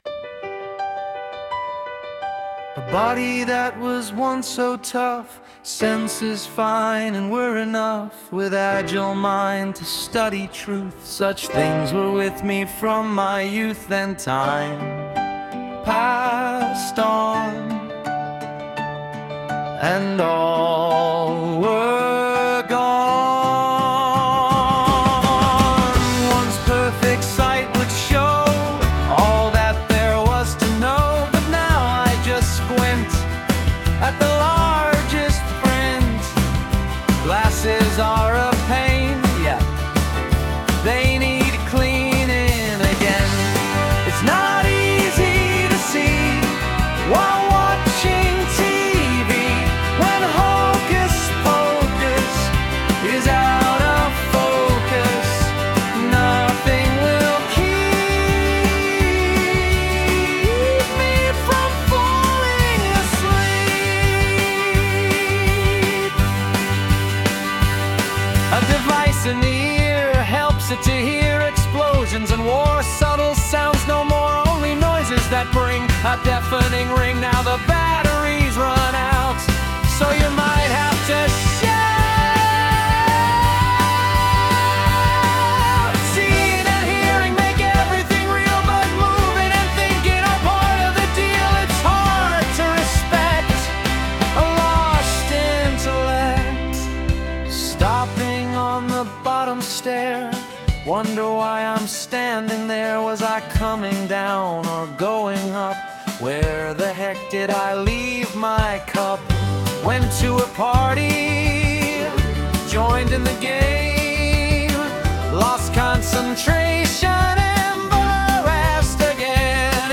with original music: PAGE 2